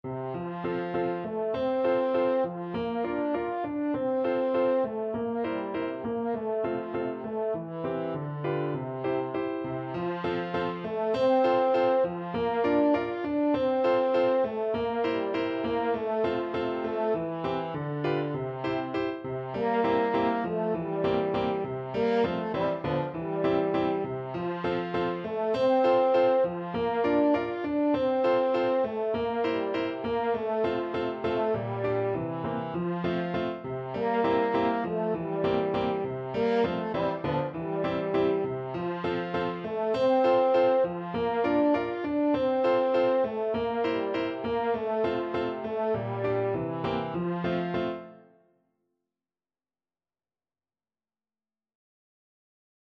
4/4 (View more 4/4 Music)
~ = 100 Frisch und munter
Classical (View more Classical French Horn Music)